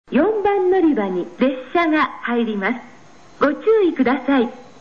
音質：D〜E
４番のりば 接近放送 （当駅止まり）　(23KB/04秒)